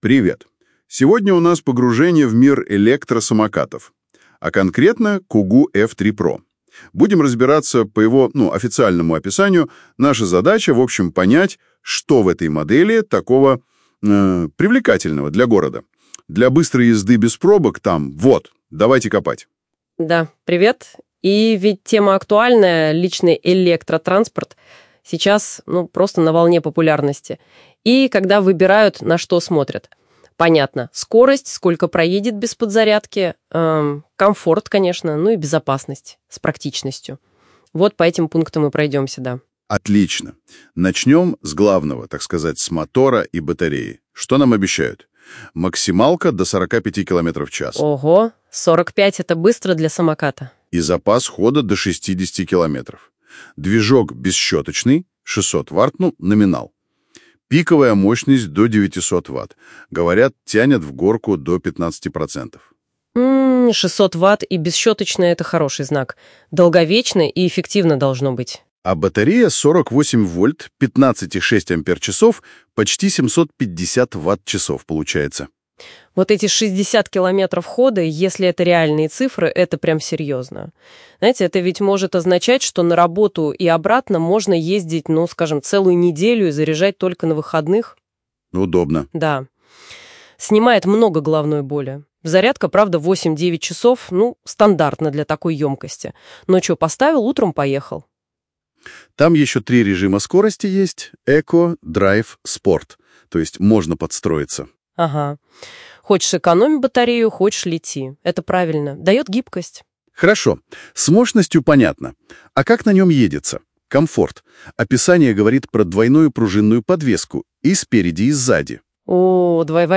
kugoo-f3-pro_-gorodskoj-samokat-ili-smena-pravil-igry_-polnyj-obzor.mp3